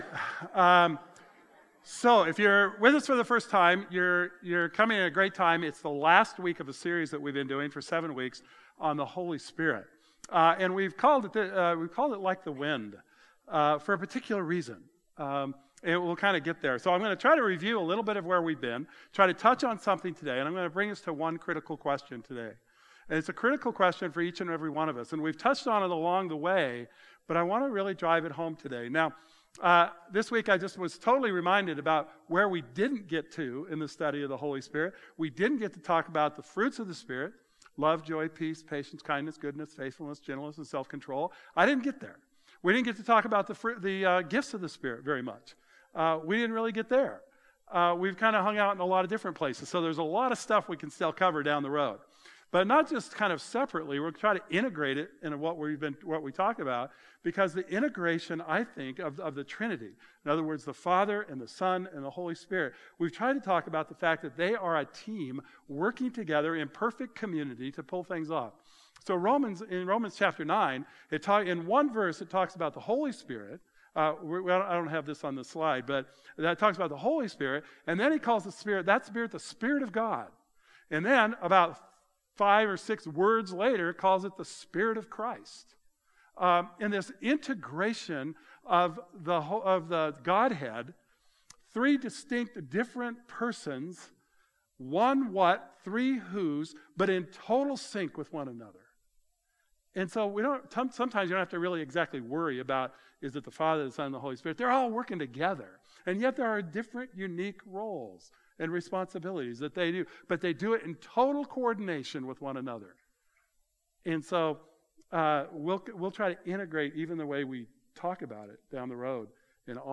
Today's message finishes the sermon series "Like the Wind" which has explored the Holy Spirit.